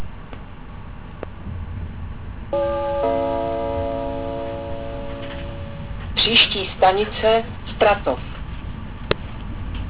Přidávám hlášení z vlaku linky S2. Omlouvám se za mírně horší kvalitu nahrávek..